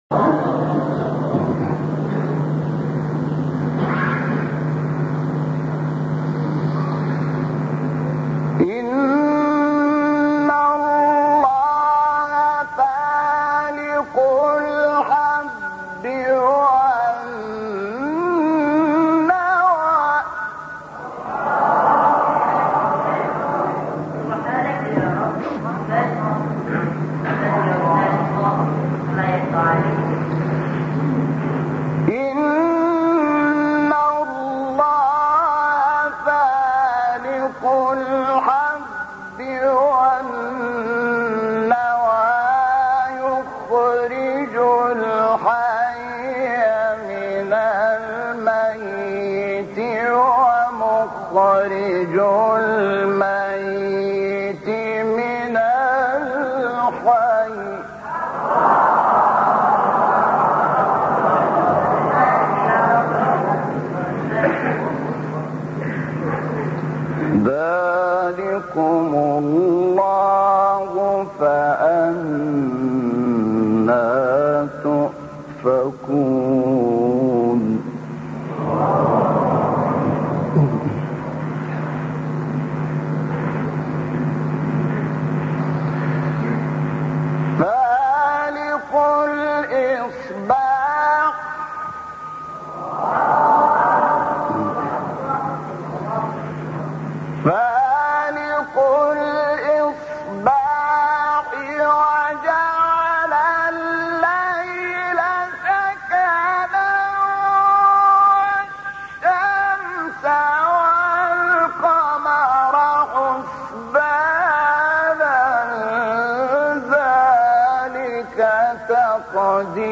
سایت قرآن کلام نورانی - شحات - رست (2).mp3
سایت-قرآن-کلام-نورانی-شحات-رست-2.mp3